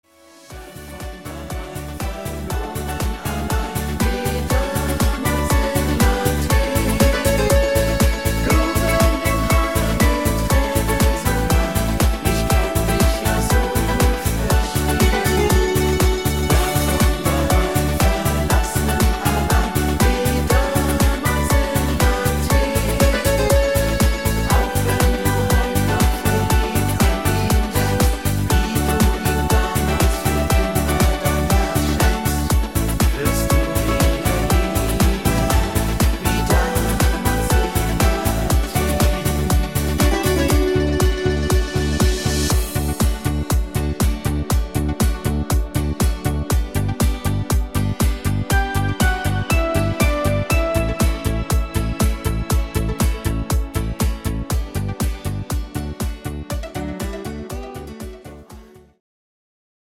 fetziger neuer Schlager